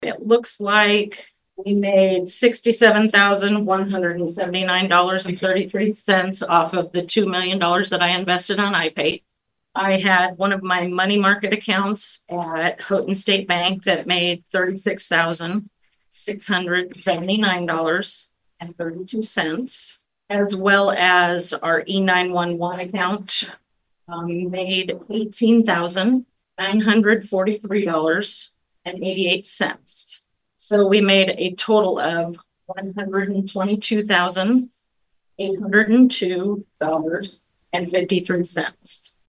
Montgomery County BOS meeting, 1-14-25
The Board then received a report from Montgomery County Treasurer Jackie Porter, with regard to Banking Investments and Interest.